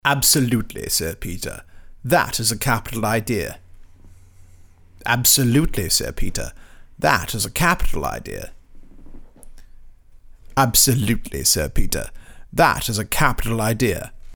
british-accent-first-demo-of-article.mp3